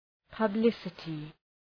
Προφορά
{pəb’lısətı}